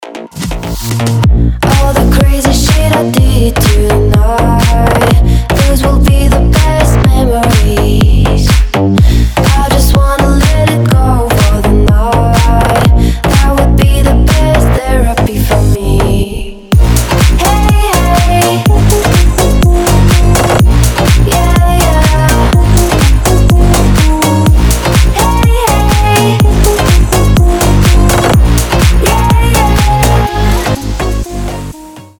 • Качество: 320, Stereo
громкие
зажигательные
веселые
басы
энергичные
slap house